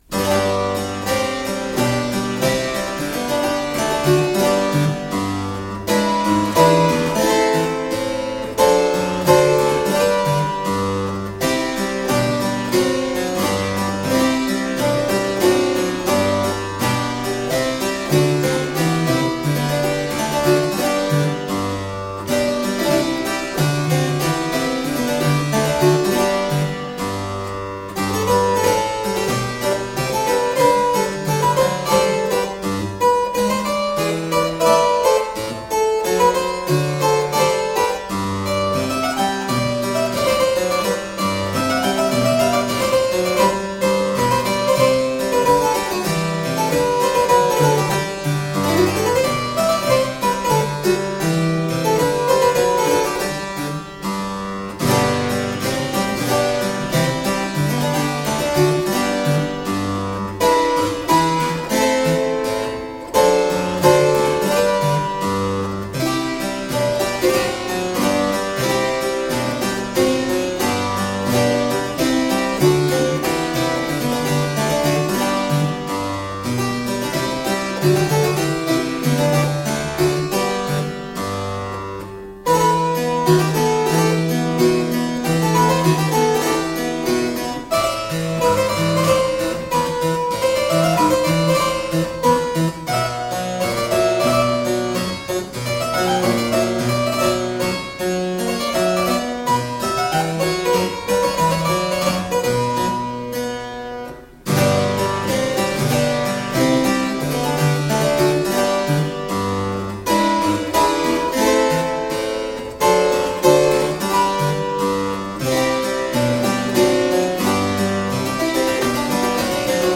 Solo harpsichord music.
Classical, Baroque, Renaissance, Instrumental
Harpsichord